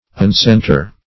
Search Result for " uncenter" : The Collaborative International Dictionary of English v.0.48: Uncenter \Un*cen"ter\, Uncentre \Un*cen"tre\, v. t. [1st pref. un- + center.]